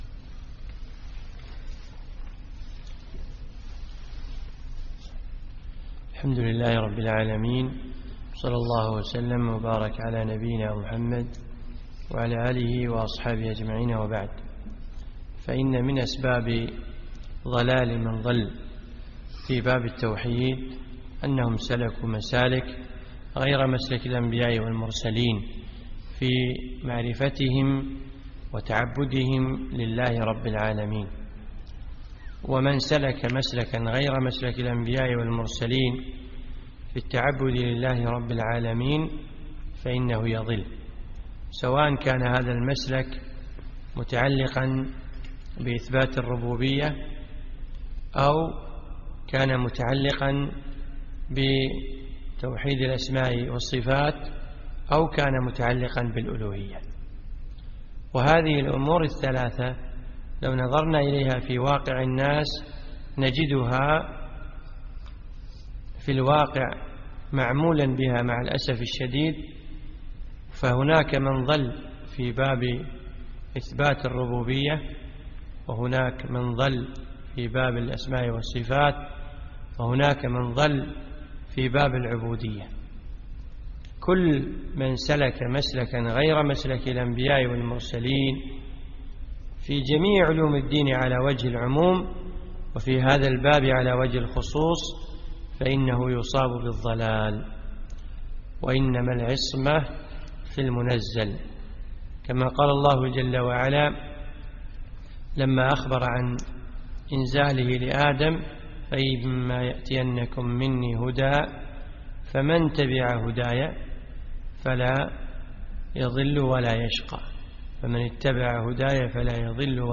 من دروس الشيخ في دولة الإمارات
الدرس الثاني